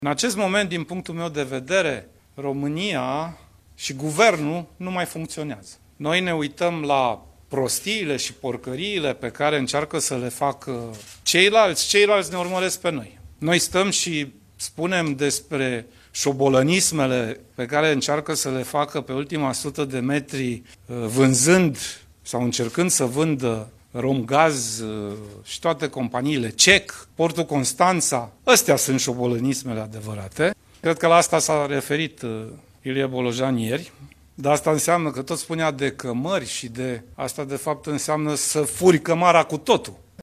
„În acest moment, din punctul meu de vedere, România și Guvernul nu mai funcționeză. Ne uităm la prostiile și porcăriile pe care încearcă să le facă ceilalți, ceilalți ne urmăresc pe noi. Noi stăm și spunem despre șobolănismele pe care încearcă să le facă pe ultima sută de metri, vânzând sau încercând să vândă Romgaz, CEC, Portul Constanța. Astea-s șobolănismele adevărate. Cred că la asta s-a referit Ilie Bolojan ieri, că tot spunea de cămări, asta de fapt înseamnă să furi cămara cu totul”, a declarat liderul social-democraților la o conferință de presă la Timișoara.